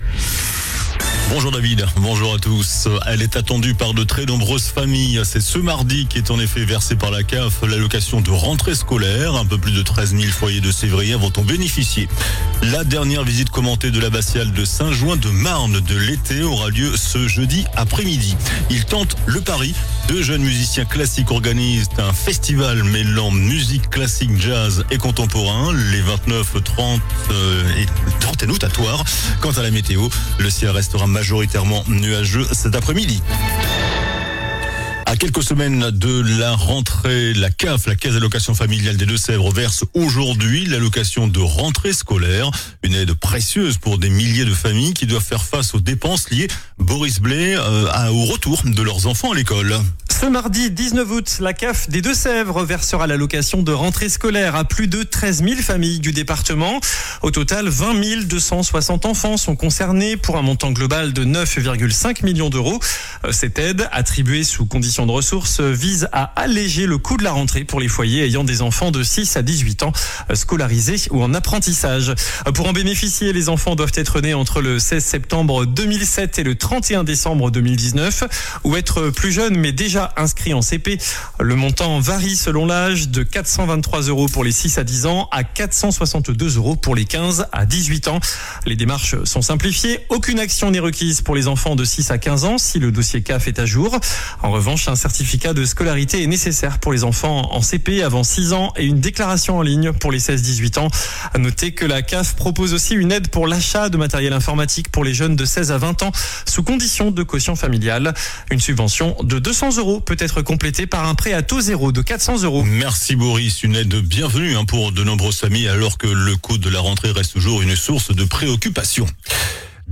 JOURNAL DU MARDI 19 AOÛT ( MIDI )